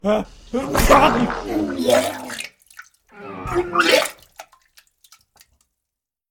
Play Kichnięcie Z Niespodzianką Extra - SoundBoardGuy
Play, download and share Kichnięcie z niespodzianką extra original sound button!!!!
kichniecie-z-niespodzianka-extra.mp3